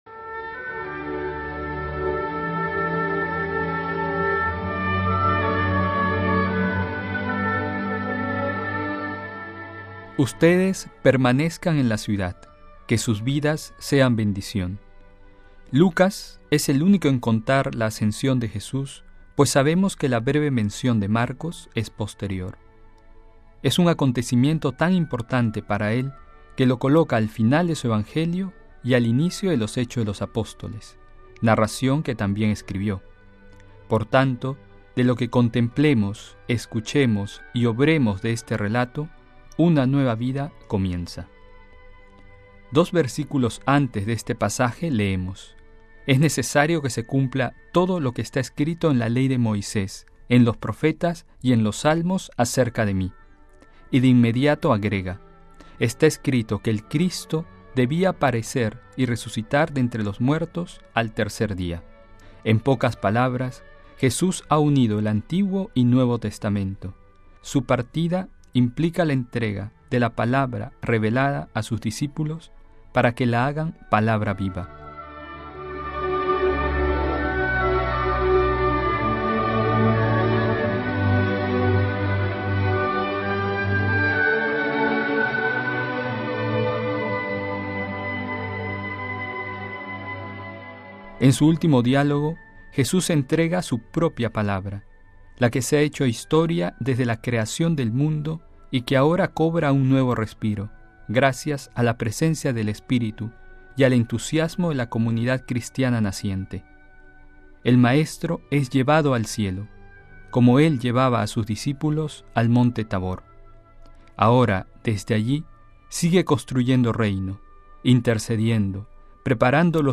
reflexión